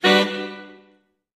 Music Effect; Jazz Saxophone Chords.